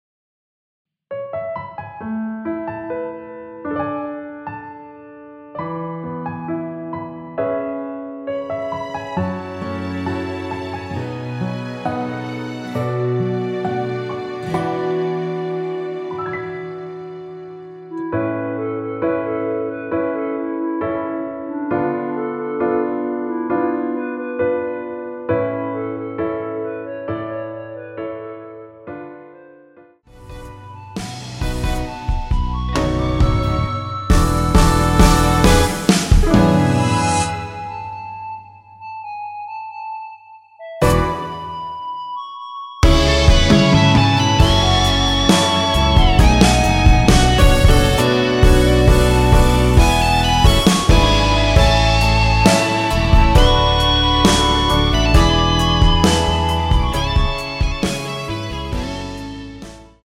원키에서(+2)올린 멜로디 포함된 MR입니다.(미리듣기 확인)
앞부분30초, 뒷부분30초씩 편집해서 올려 드리고 있습니다.
중간에 음이 끈어지고 다시 나오는 이유는